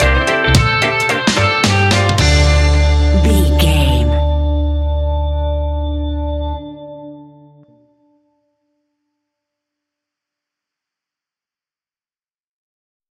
Aeolian/Minor
D
reggae instrumentals
laid back
chilled
off beat
drums
skank guitar
hammond organ
percussion
horns